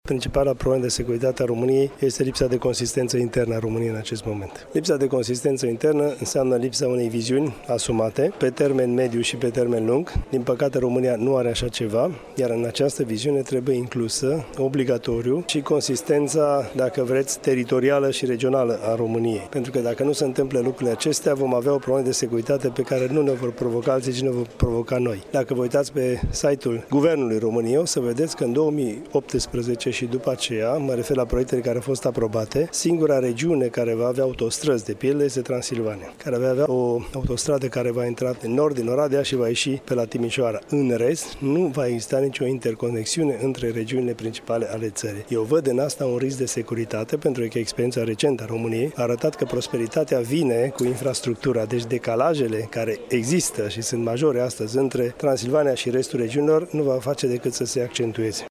Lipsa unei viziuni pe termen mediu şi lung în domeniul dezvoltării infrastructurii poate determina una dintre problemele majore ale României, a precizat astăzi, la Iaşi, analistul Dan Dungaciu, în cadrul conferinţei cu tema „Provocări de securitate în Sud-Estul Europei”: